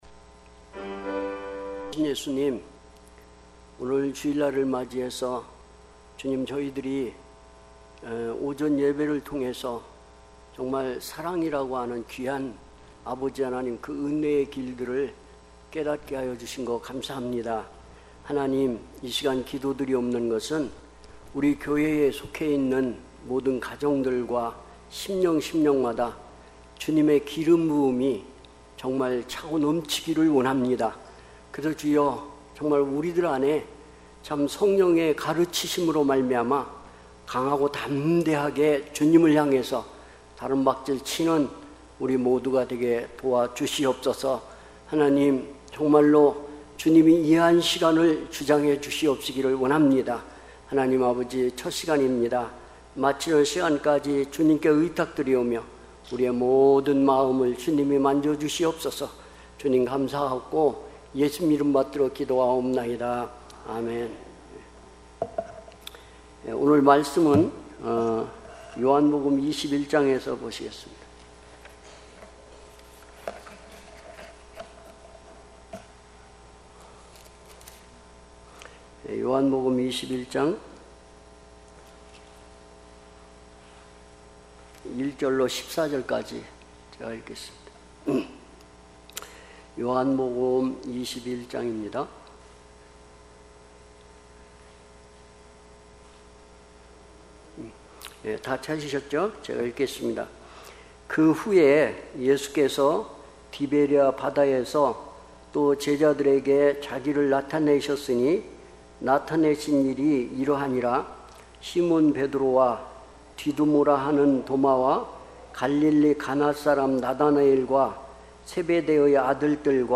특별집회 - 요한복음 21장 1-14절